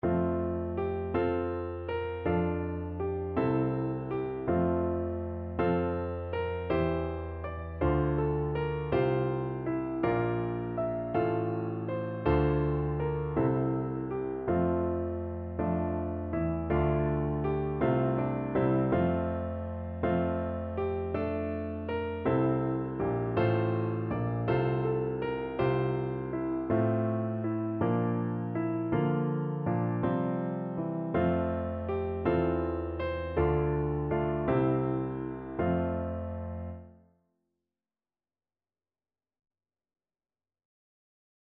Traditional Trad. Of the Fathers Heart Begotten (from Piae Cantiones) Piano version
No parts available for this pieces as it is for solo piano.
3/4 (View more 3/4 Music)
F major (Sounding Pitch) (View more F major Music for Piano )
Gentle one in a bar (. = c. 54)
Piano  (View more Intermediate Piano Music)
Traditional (View more Traditional Piano Music)